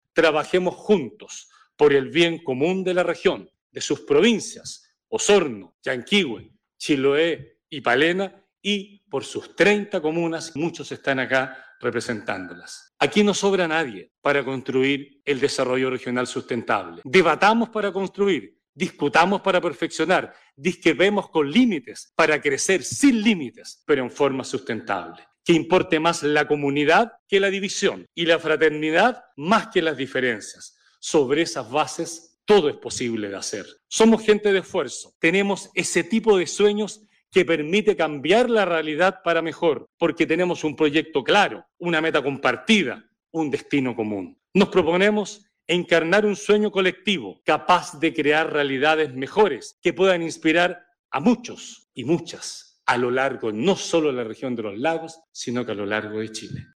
El nuevo Gobernador Regional indico que se debe trabajar juntos para lograr un desarrollo regional sustentable: